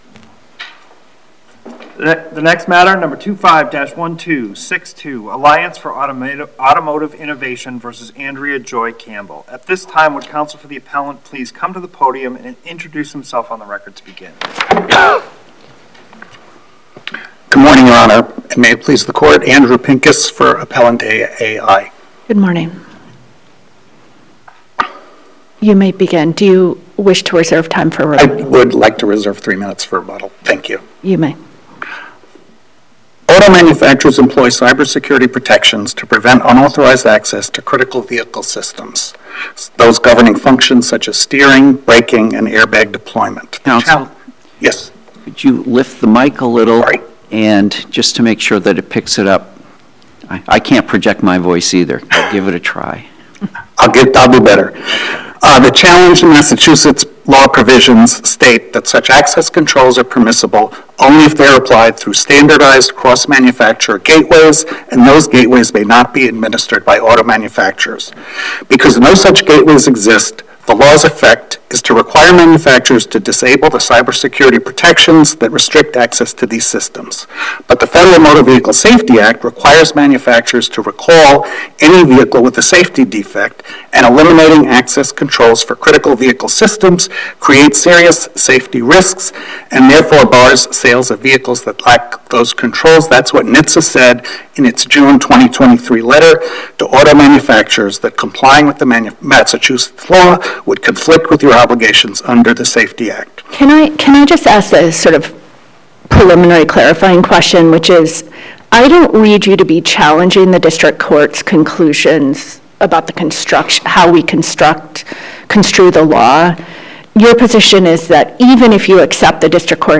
At least one member of a panel of the U.S. Court of Appeals for the First Circuit said she was “troubled” by aspects of the Massachusetts Data Access Law—approved overwhelmingly by voters in a November 2020 ballot initiative—during oral argument on February 3, 2026 in an appeal brought by the Alliance for Automotive Innovation (“Auto Innovators”) arguing the state law is preempted by the Federal Motor Vehicle Safety Act (FMVSA).